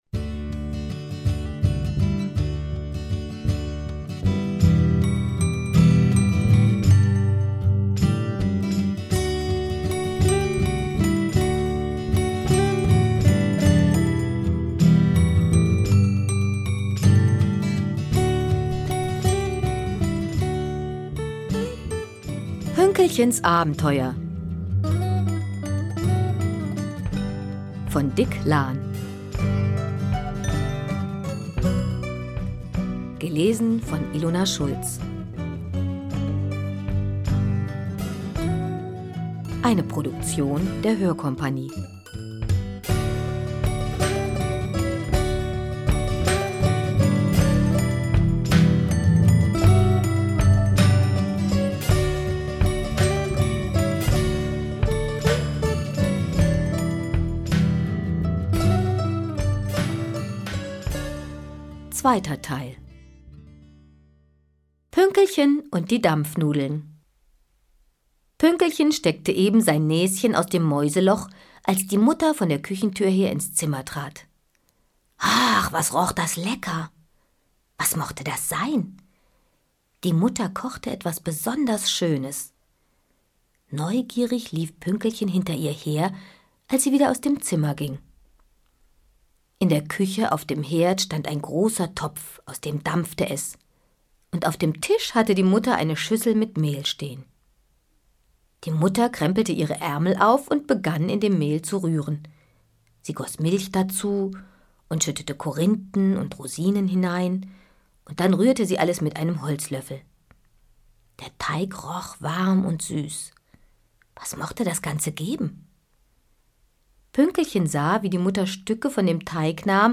Schlagworte Hörbuch • Hörbuch für Kinder/Jugendliche • Hörbuch; Lesung für Kinder/Jugendliche • Kinder • Kinder-CDs (Audio) • Lesung • Spaß • Witz